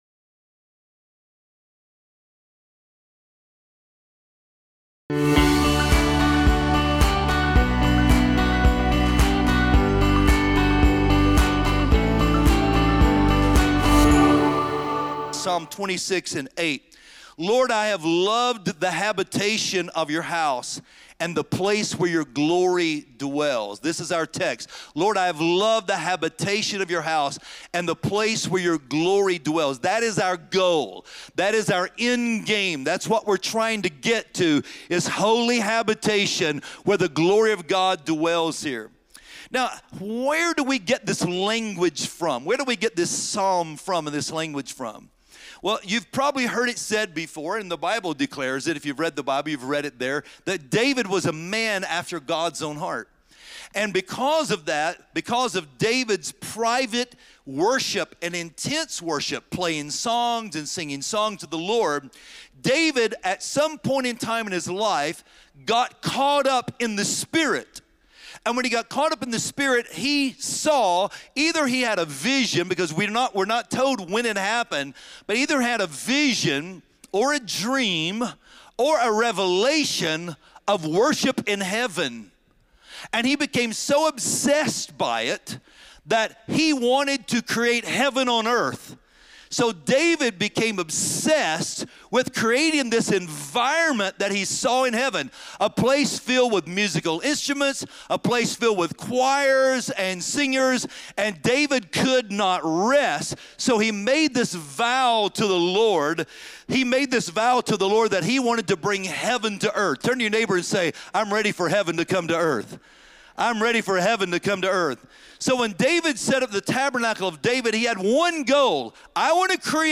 Join us this week for the sermon “A Place for the Lord!”